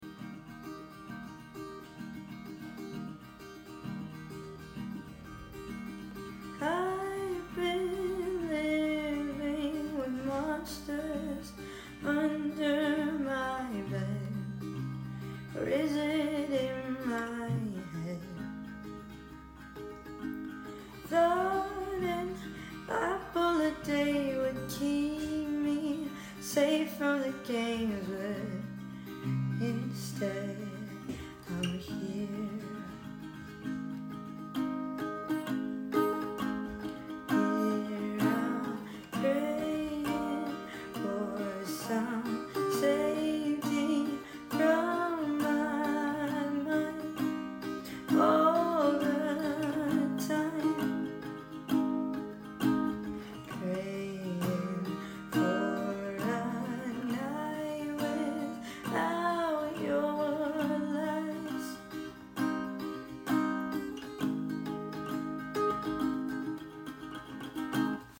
it’s an odd freestyle